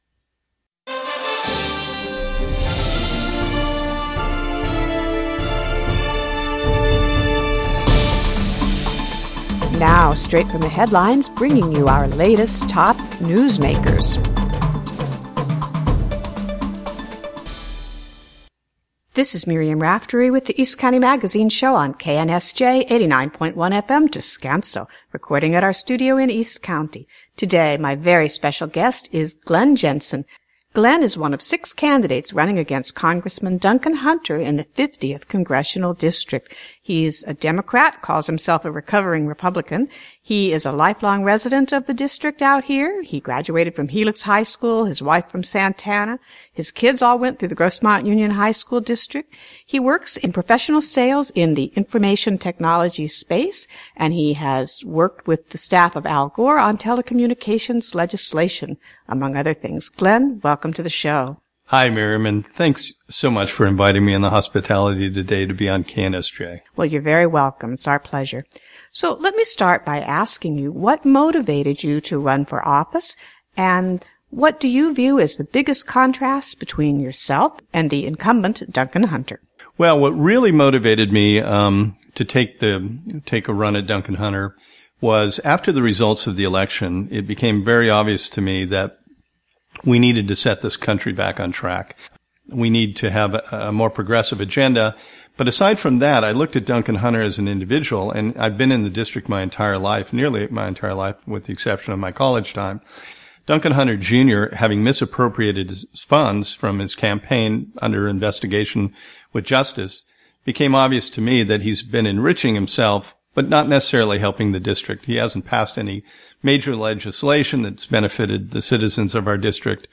East County Magazine Live! Radio Show